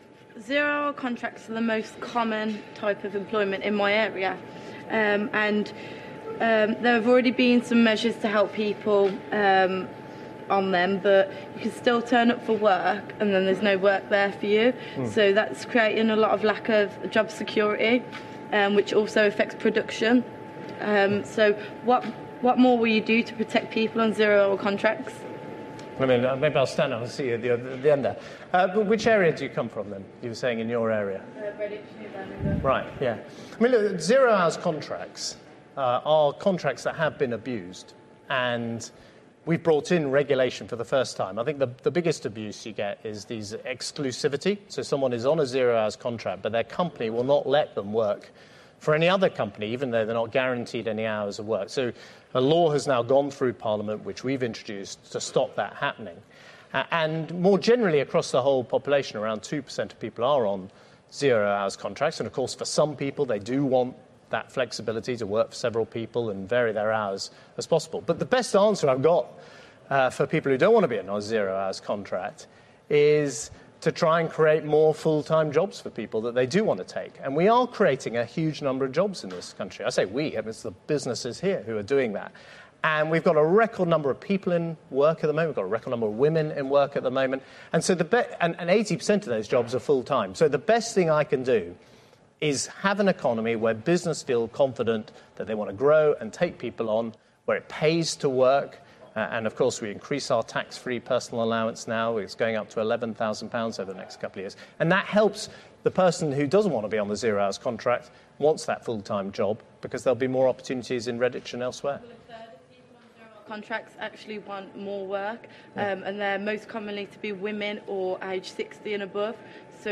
George Osborne is challenged on the issue of zero-hour contracts by a young voter on Sky News's Ask The Chancellors. The chancellor said that in some cases employees had been 'abused'. 23 March 2015.